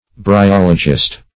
Bryologist \Bry*ol"o*gist\, n. One versed in bryology.